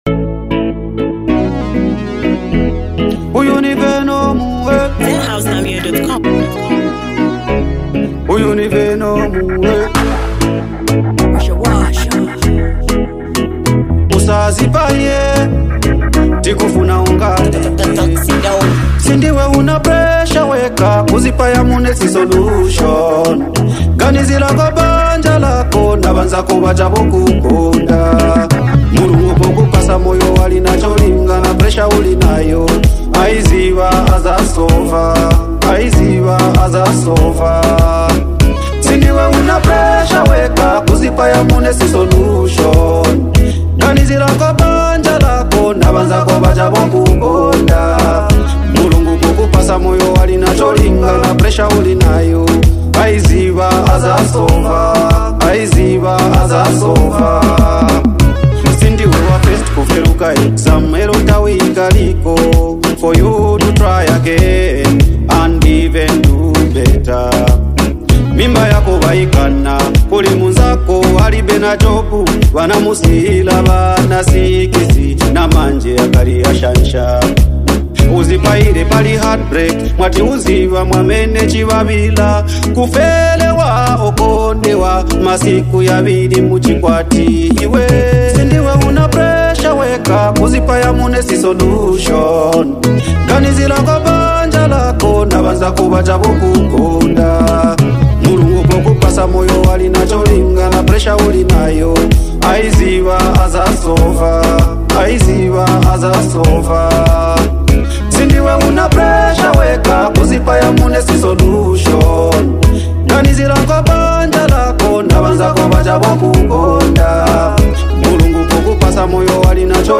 With deep lyrics and moving beats